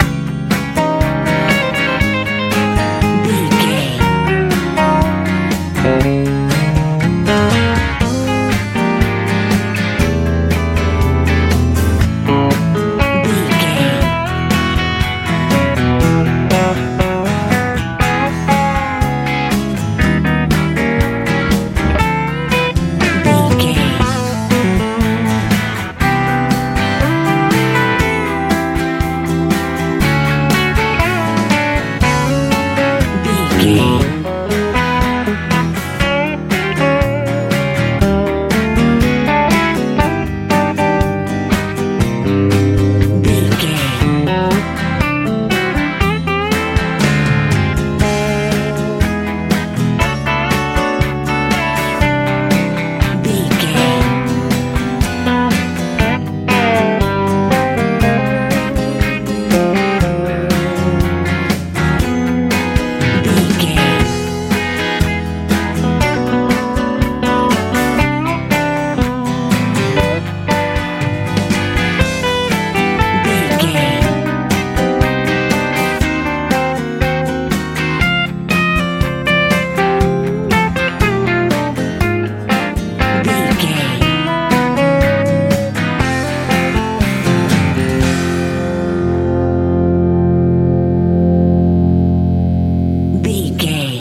Ionian/Major
cool
confident
playful
acoustic guitar
electric guitar
bass guitar
drums
happy